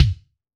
B.B KICK 6.wav